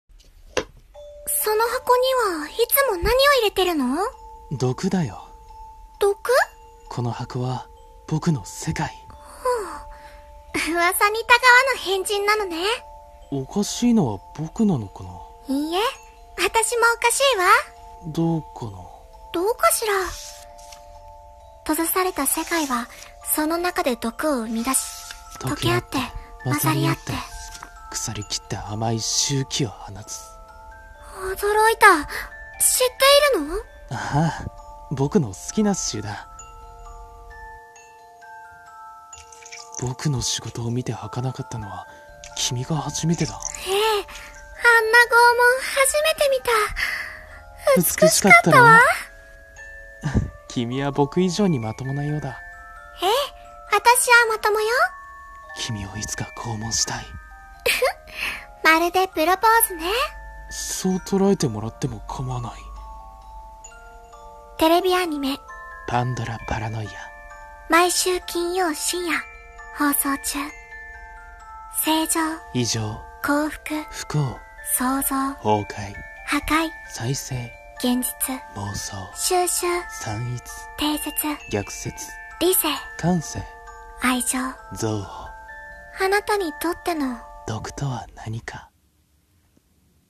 【声劇台本】
［アニメ予告風］